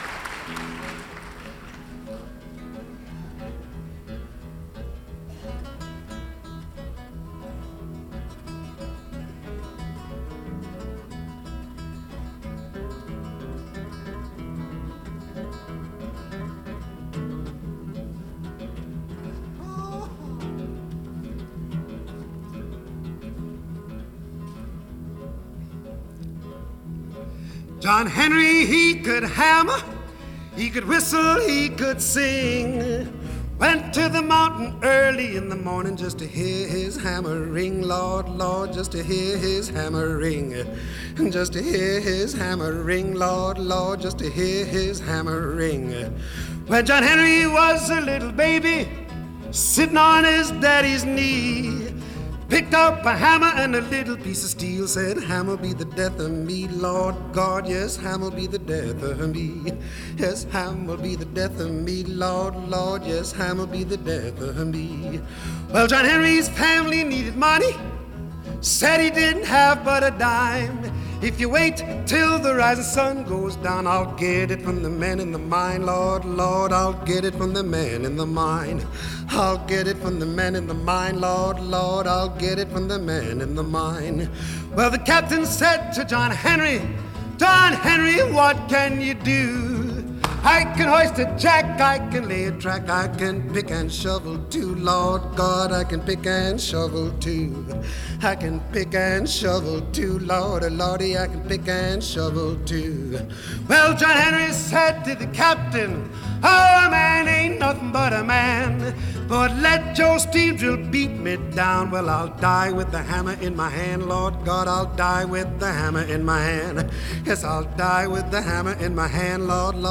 Genre: Folk
Recorded at Carnegie Hall, April 19 and 20, 1959.